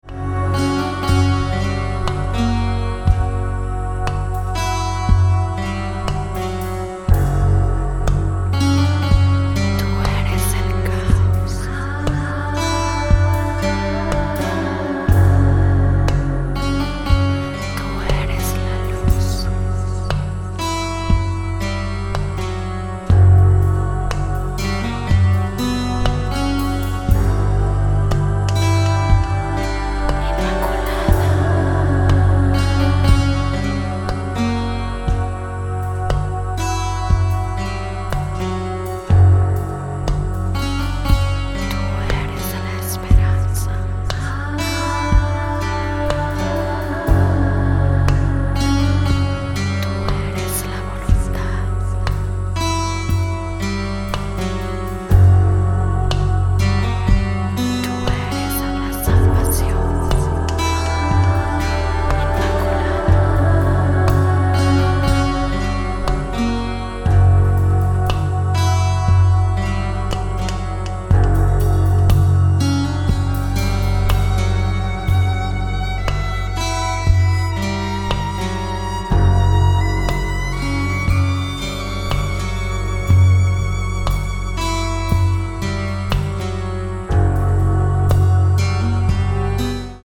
mandolins, guitar, bass, piano, keyboards, percussion
spoken word and vocals